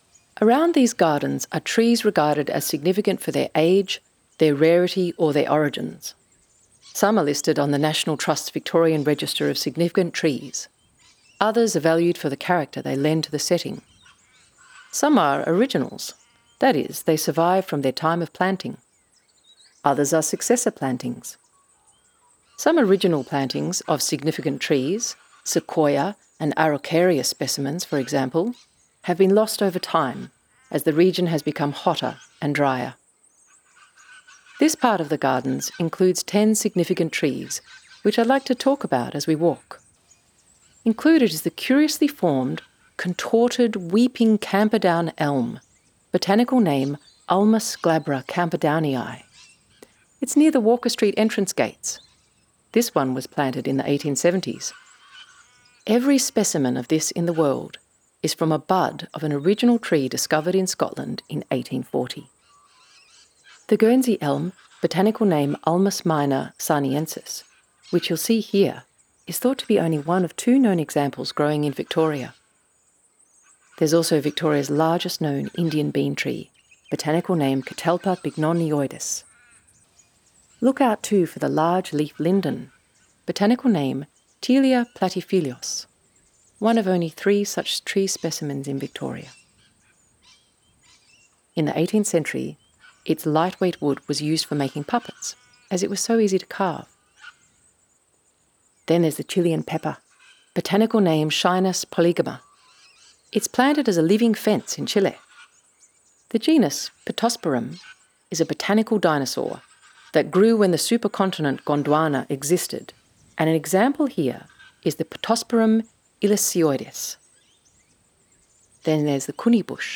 Audio tour
cbg-audio-guide-significant-trees.wav